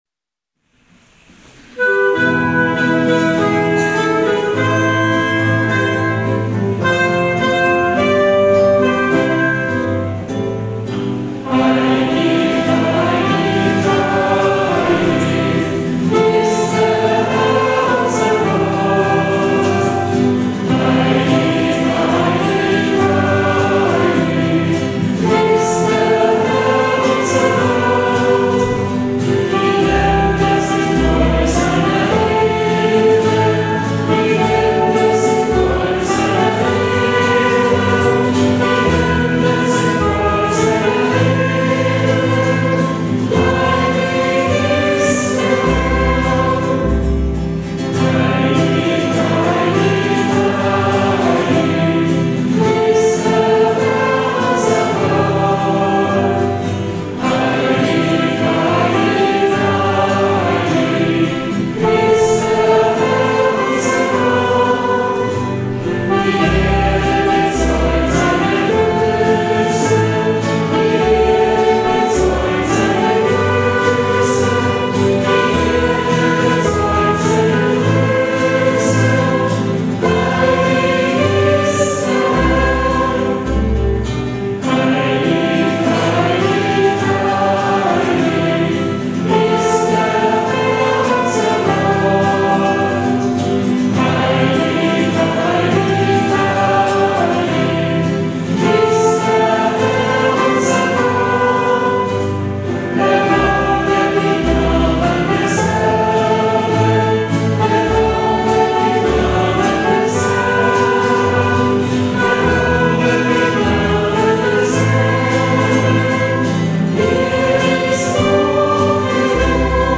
Begleitet von der Spielgruppe
Den Regen an diesem Tag kann man zwar am Beginn und am Ende der Lieder hören.
Den kräftigen Volksgesang aber auch !